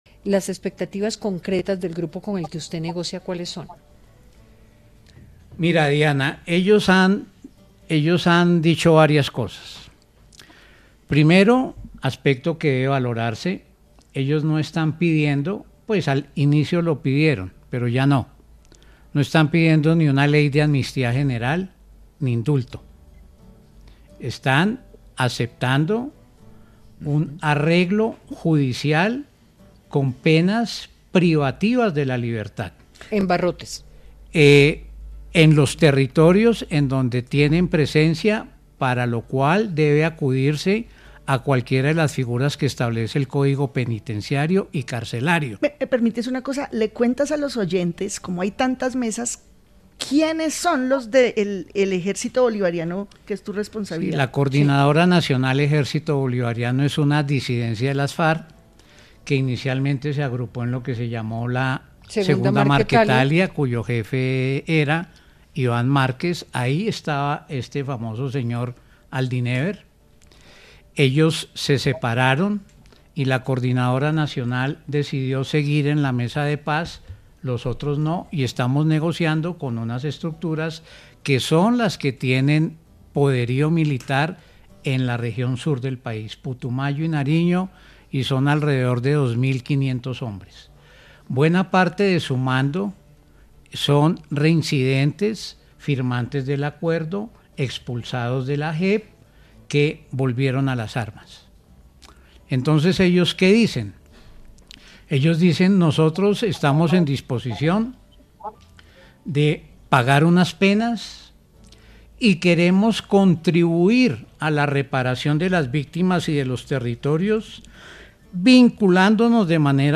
En diálogo con Hora20 de Caracol Radio, el jefe negociador del Gobierno con la Coordinadora Nacional Ejército Bolivariano, Armando Novoa, planteó que esta organización armada no está pidiendo ley de amnistía genera ni indulto, “están aceptando un arreglo judicial con penas privativas de la libertad en los territorios donde tienen presencia”.